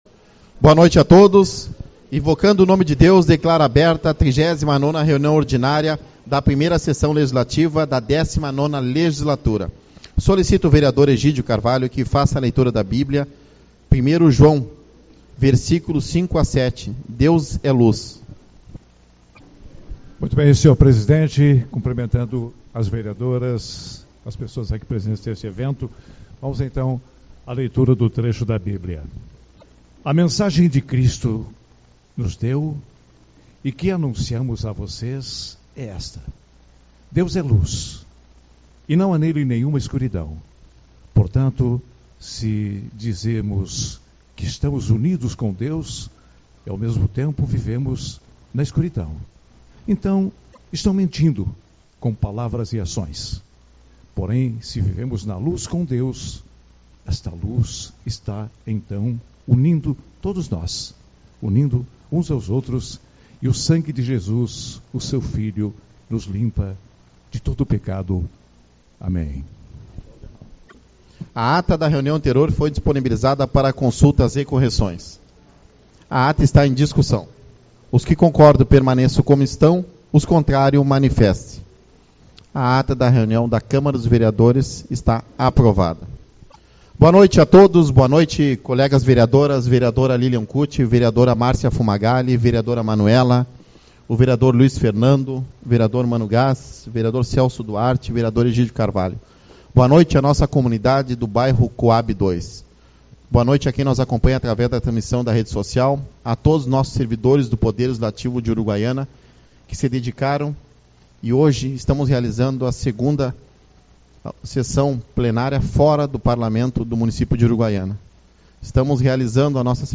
26/06 - Reunião Ordinária-Cohab II